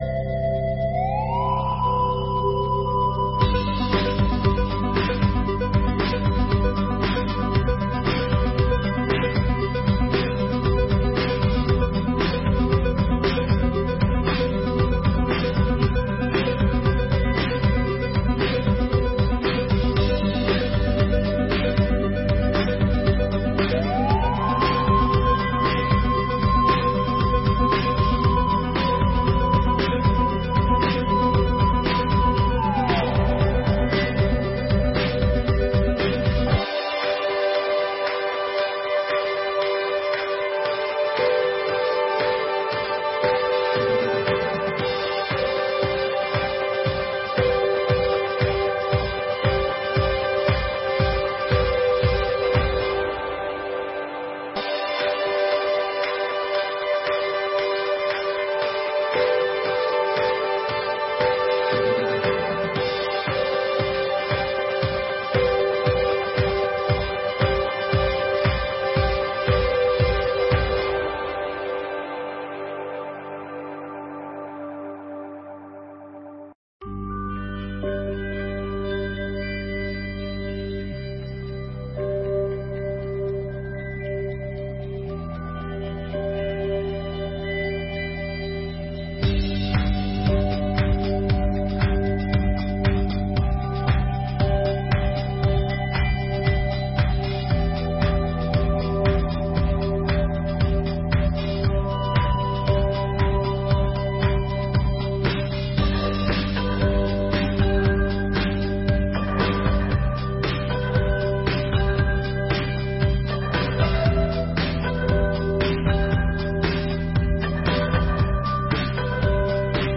17ª Sessão Ordinária de 2023